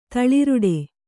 ♪ taḷiruḍe